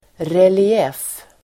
Ladda ner uttalet
Folkets service: relief relief substantiv, relief Uttal: [reli'ef:] Böjningar: reliefen, reliefer Definition: bild som är upphöjd ur bakgrunden (a picture which is raised from the background) relief substantiv, relief